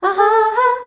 AHAAA   G.wav